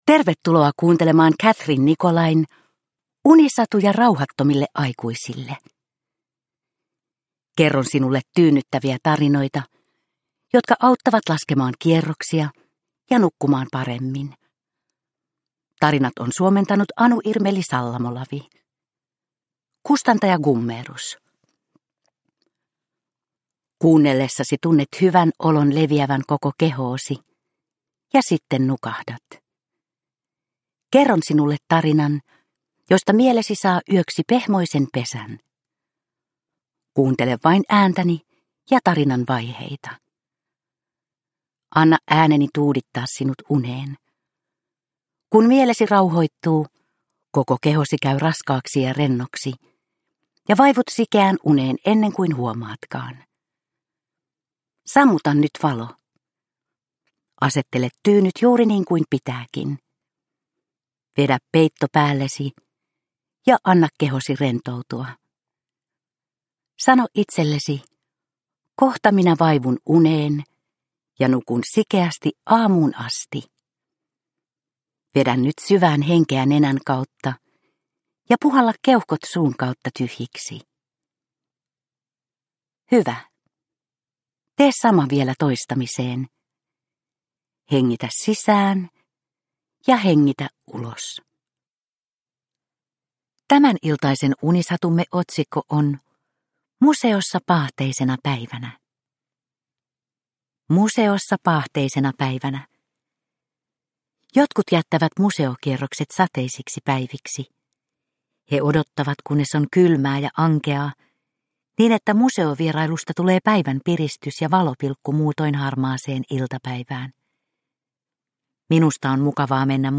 Unisatuja rauhattomille aikuisille 45 - Museossa paahteisena päivänä – Ljudbok – Laddas ner